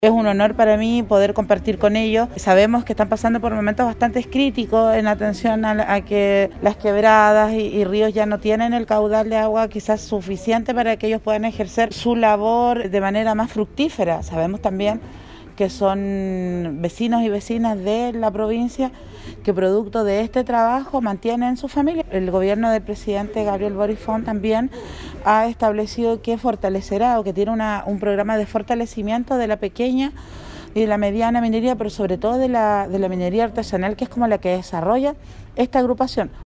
En dependencias de la Delegación Provincial de Choapa se desarrolló la ceremonia de conmemoración del décimo tercer aniversario de los lavaderos de oro de la comuna de Illapel, instancia que junto con dar a conocer la historia de estos trabajadores permitió hablar de los planes de Gobierno que hay en esta materia.
Palabras que se sumaron a las de la Delegada Provincial de Choapa, Nataly Carvajal, quien agradeció a los trabajadores el considerar a la Delegación Presidencial dentro de su celebración.
ANIVERSARIO-LAVADEROS-DE-ORO-2-Delegada.mp3